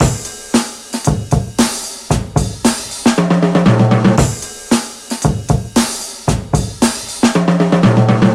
• 115 Bpm Drum Beat C# Key.wav
Free breakbeat sample - kick tuned to the C# note.
115-bpm-drum-beat-c-sharp-key-kEh.wav